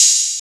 so icey boyz collab paris sesh open hat.wav